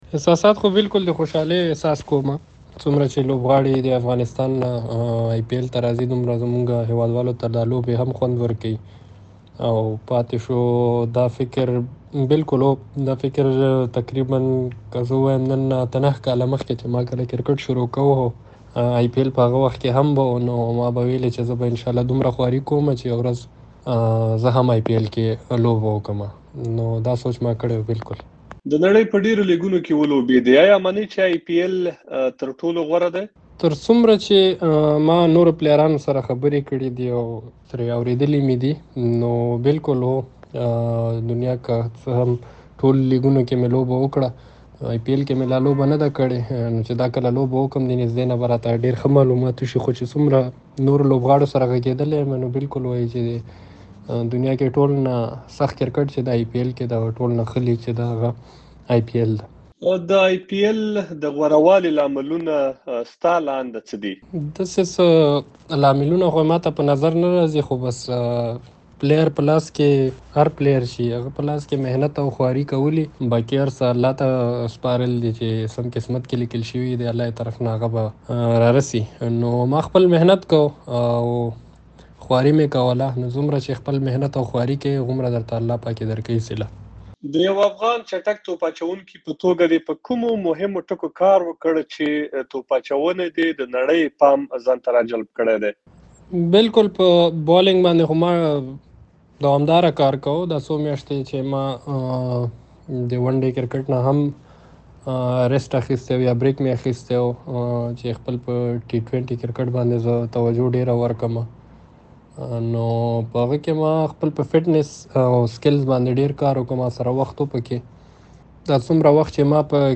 نوین الحق سره مرکه